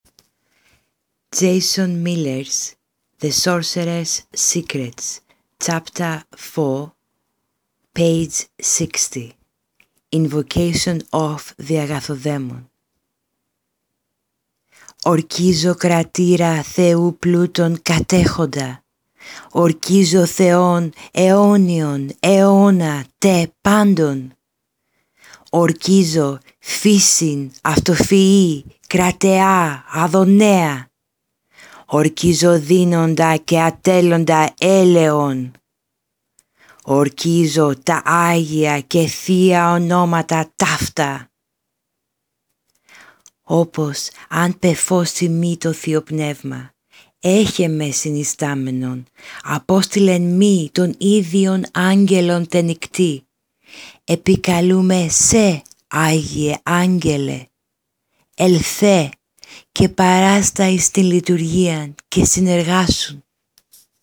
*** INVOCATION OF THE AGATHODAIMON ***